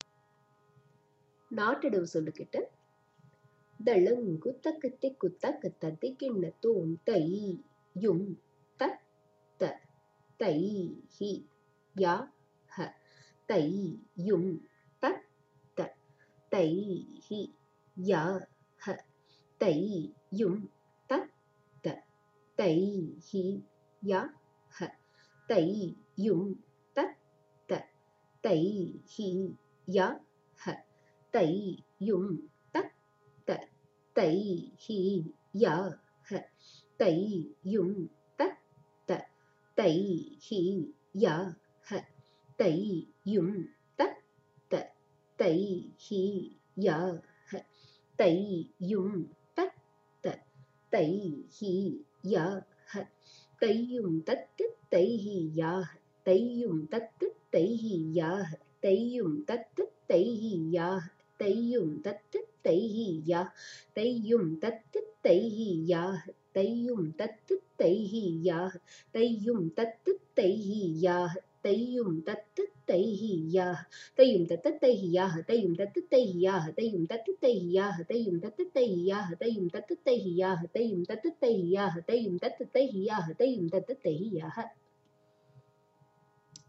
This Audio gives the Bols or syllables for the whole of Natta Adavu. This is sung in three speeds for the purpose of practice.